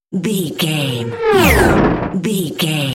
Whoosh electronic shot
Sound Effects
Atonal
futuristic
high tech
whoosh